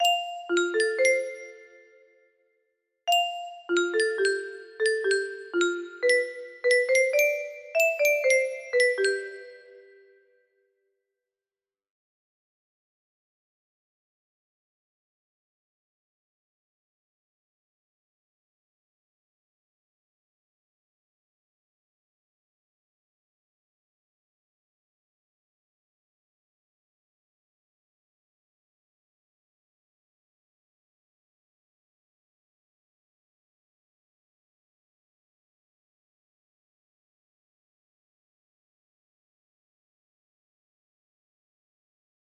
Track 6 MIDI out #5 music box melody